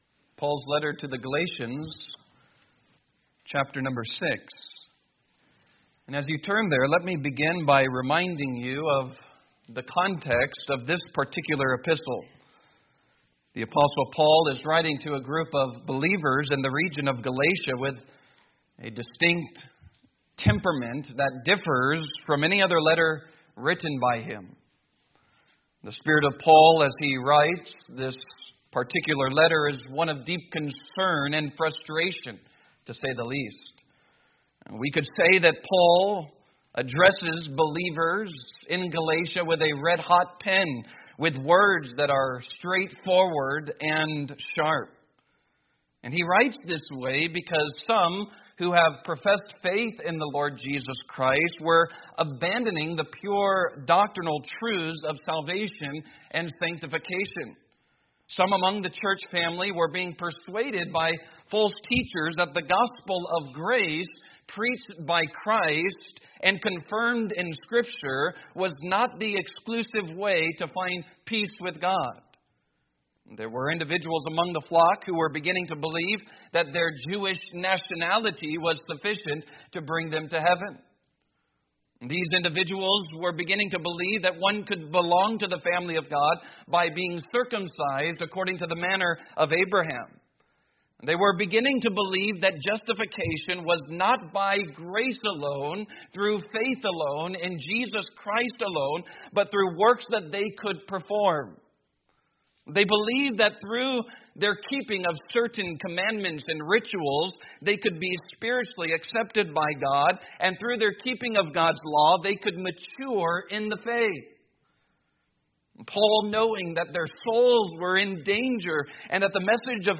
Sunday Morning Sermons – Calvary Baptist Church